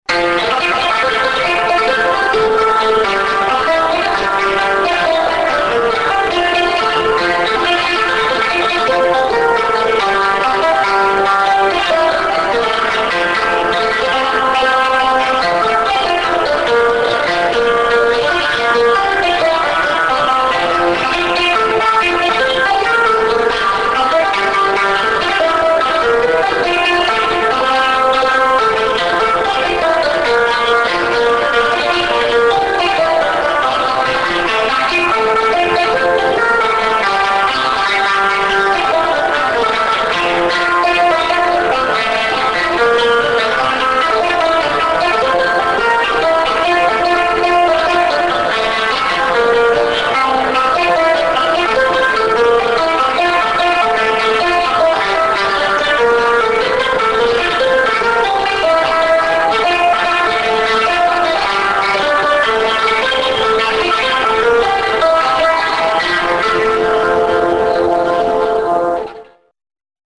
(region przeworski)
grać na cymbałach